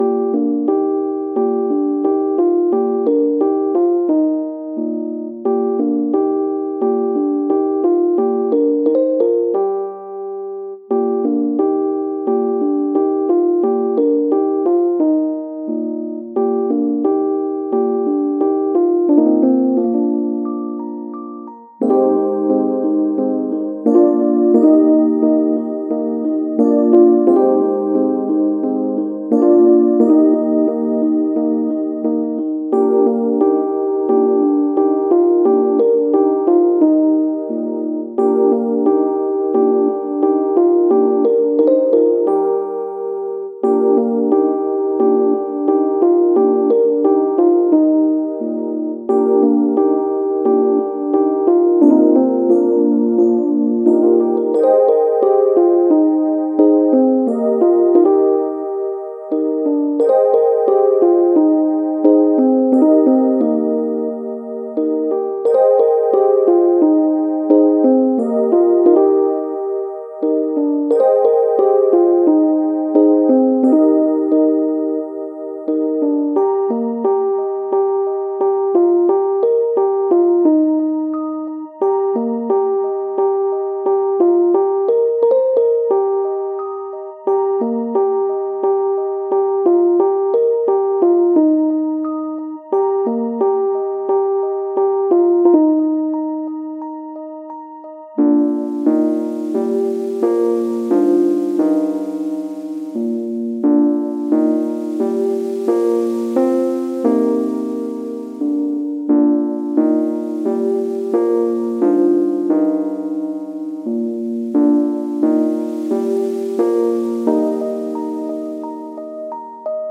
おだやか、いやしおしゃれ、ゆったり